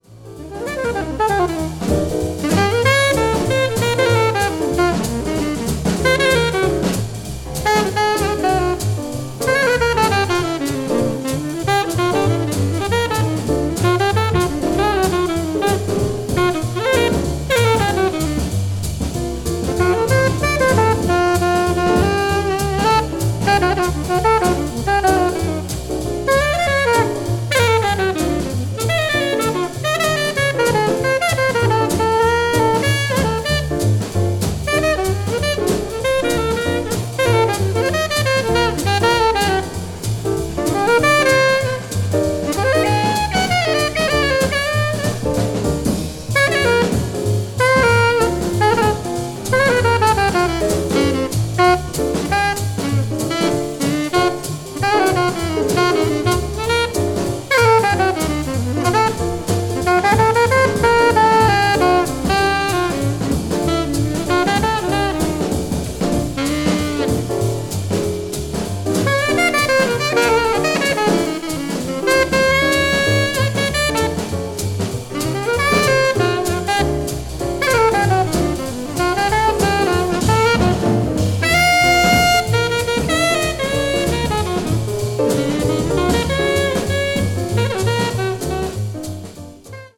media : EX/EX(some slightly noises.)
hard bop   modern jazz